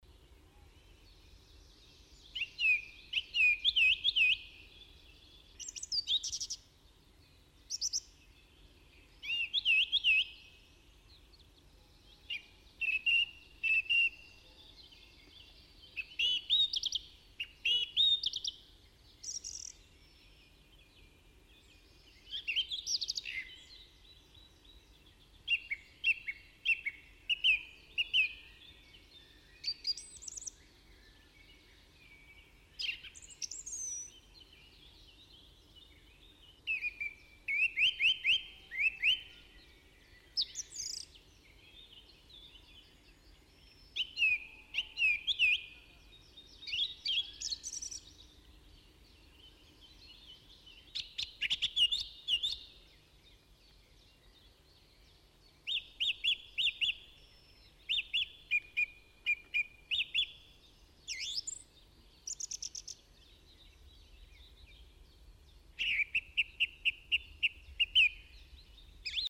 دانلود صدای سر و صدای مرغ عشق از ساعد نیوز با لینک مستقیم و کیفیت بالا
جلوه های صوتی